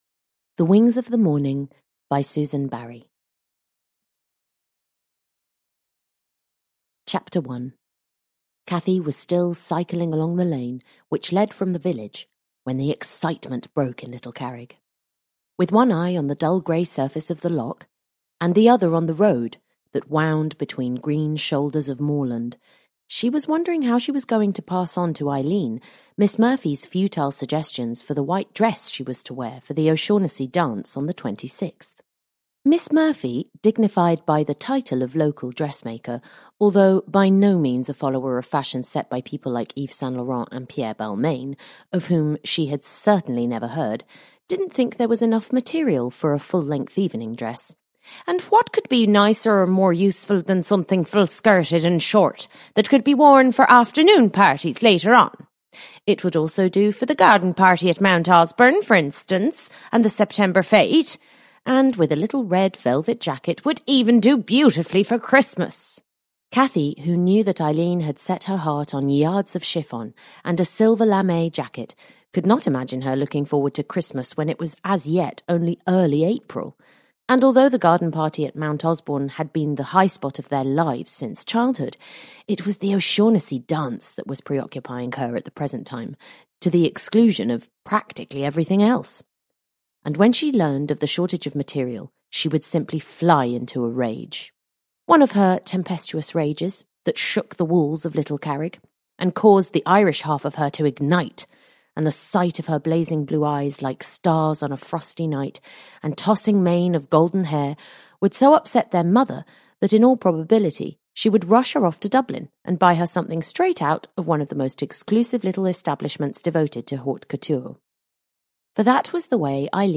The Wings of the Morning (ljudbok) av Susan Barrie